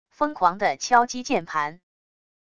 疯狂的敲击键盘wav音频